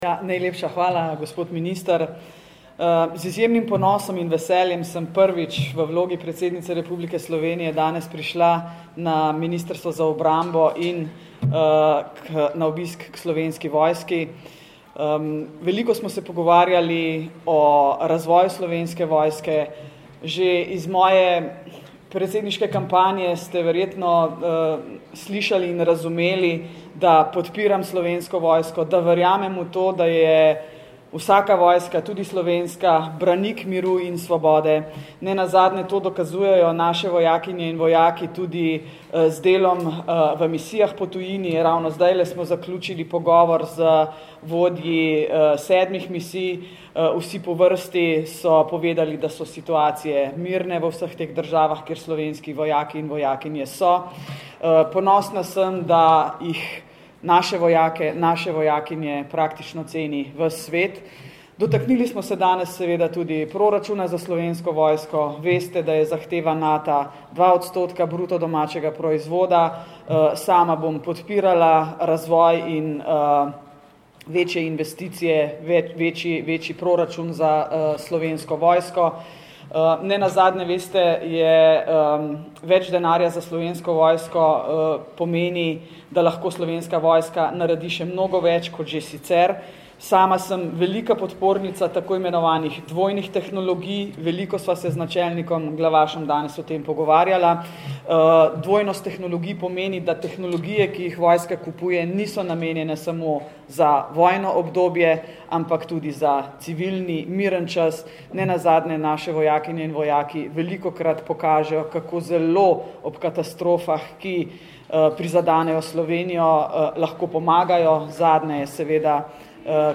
Izjava predsednice Republike Slovenije in vrhovne poveljnice obrambnih sil Nataše Pirc Musar